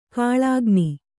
♪ kāḷāgni